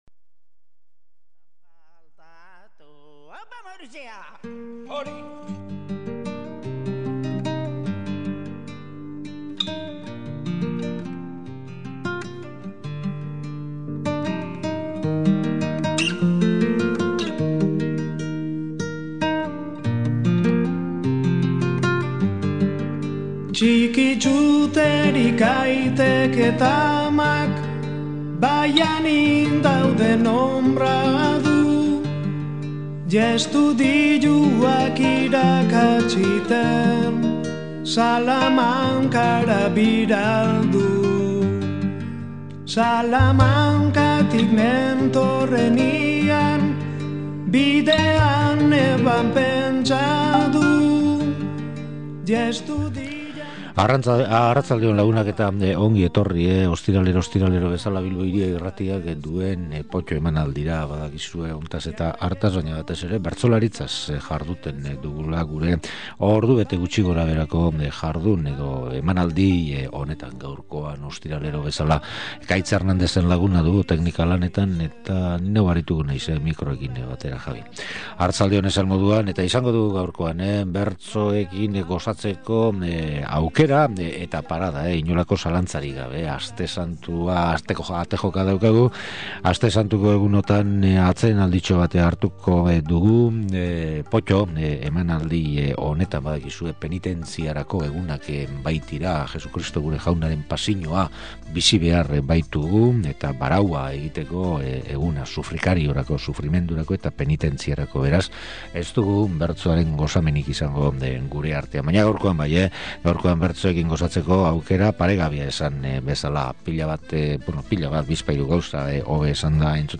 Sariketa horietako bertsorik hoberenak aukeratu eta entzungai jarri dizkigu. Sariketa girotik irten gabe, bigarren atalean aurtongo martxoaren 31n jokatu zen BBK Sariketako finala ere izan du aipagai eta hartatik Ipar Uribe eta Durangaldeko bertsolariek egindako lanaren lagin bat eskaintzen digu. Horrezaz gainera, beti bezala, hurrengo bertso saioen agenda ere badaukazu.